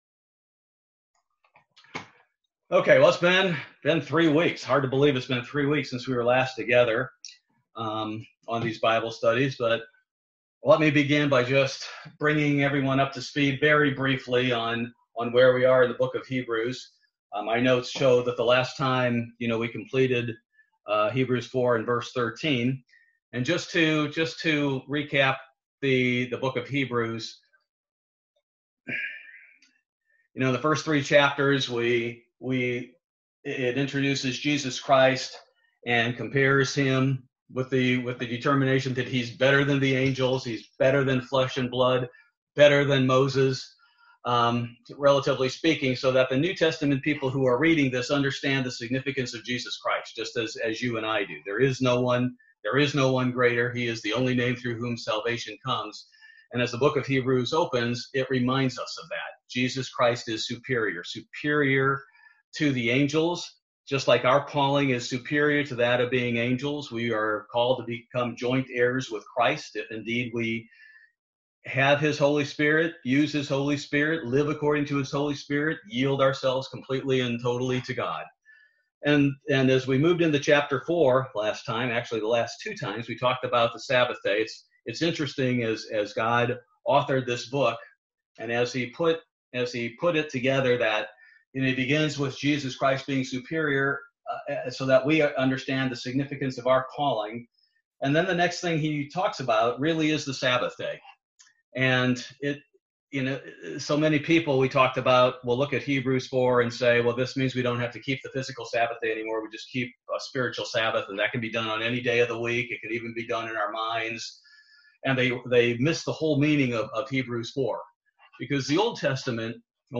Bible Study - December 16, 2020